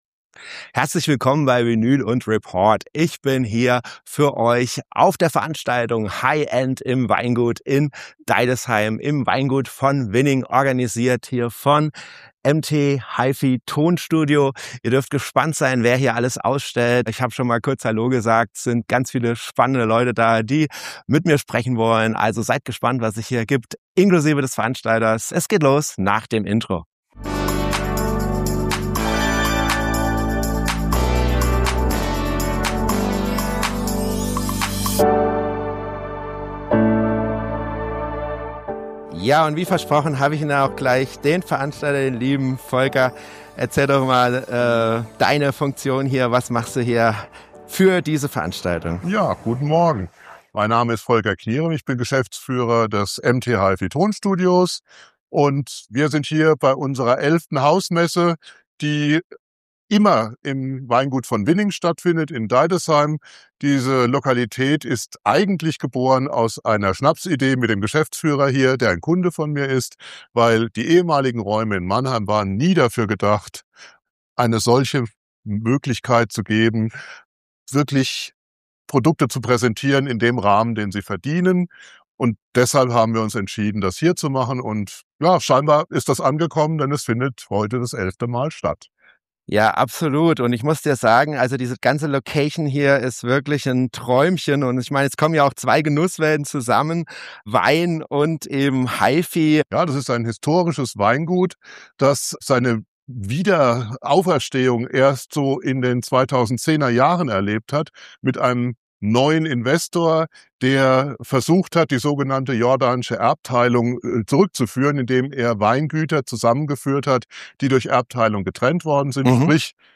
Diesmal bin ich zu Besuch im Weingut von Winning in Deidesheim bei High-End im Weingut - organisiert von MT HiFi Tonstudio GmbH. Hier trifft die Leidenschaft für Hi-Fi und Vinyl auf den Genuss von Wein.